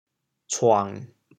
cuang1.mp3